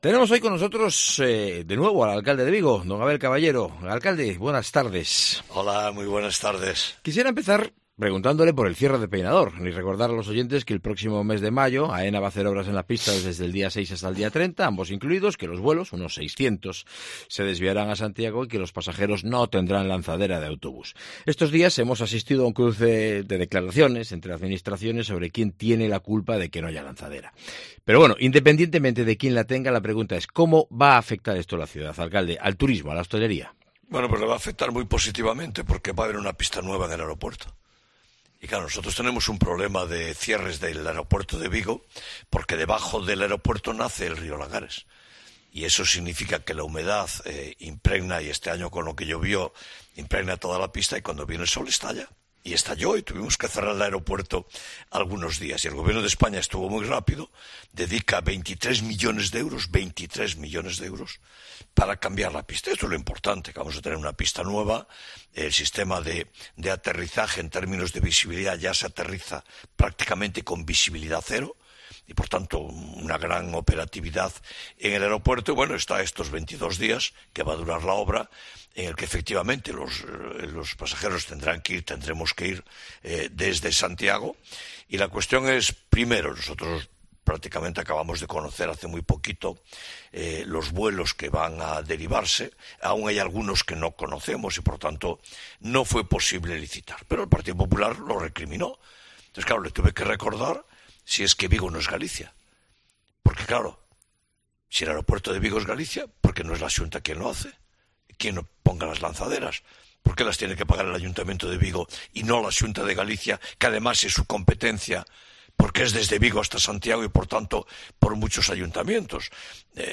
Entrevista con el alcalde de Vigo, Abel Caballero